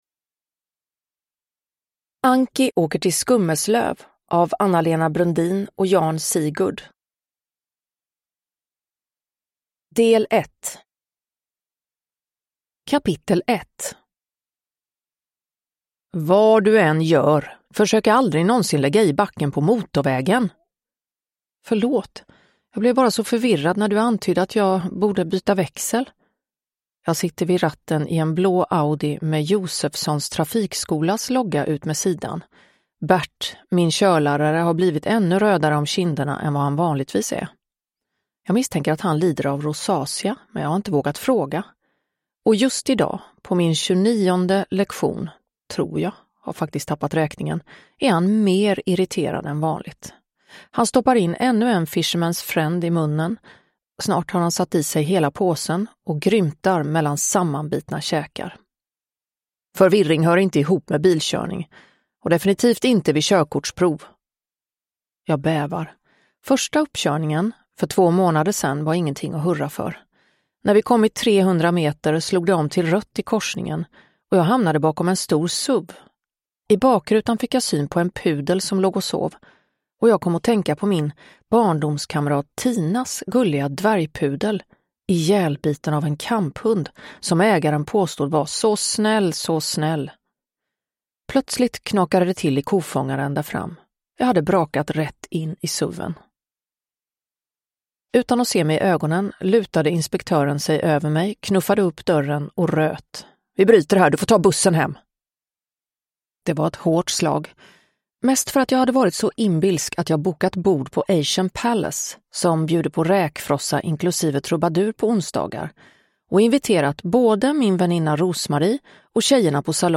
Anki åker till Skummeslöv – Ljudbok
Uppläsare: Klara Zimmergren